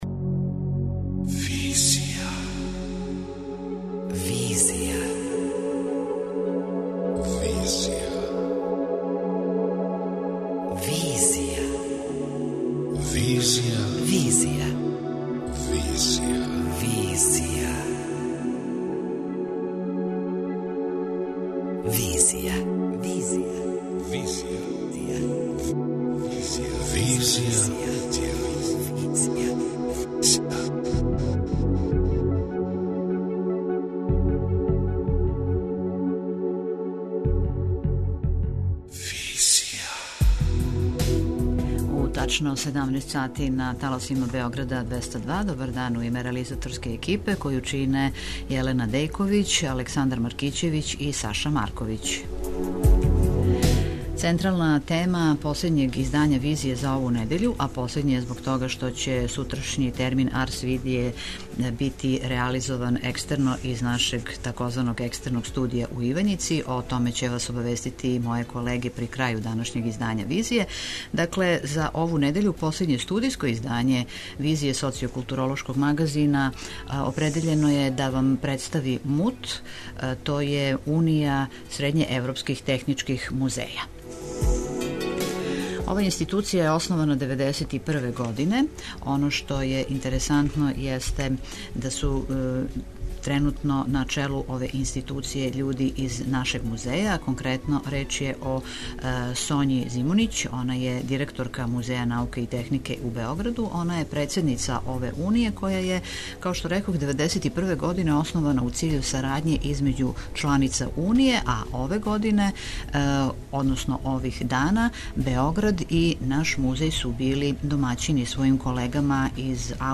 преузми : 27.42 MB Визија Autor: Београд 202 Социо-културолошки магазин, који прати савремене друштвене феномене.